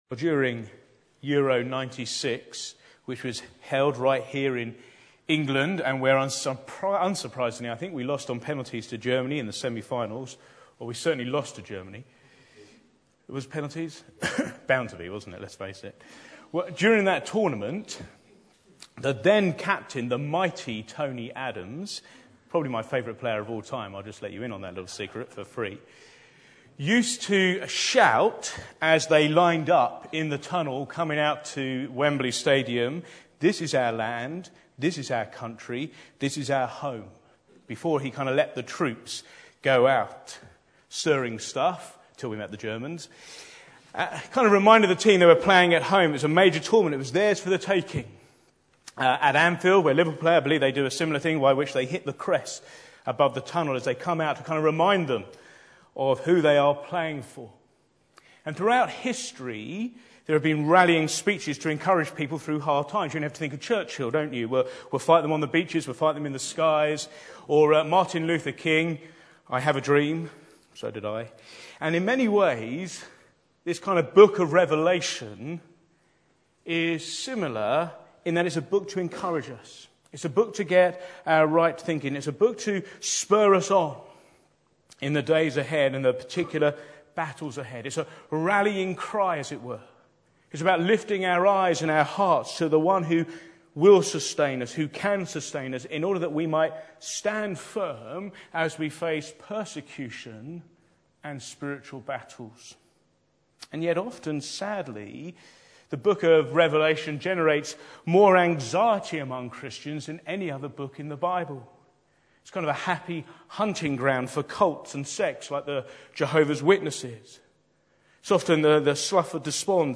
Back to Sermons The Revelation of Jesus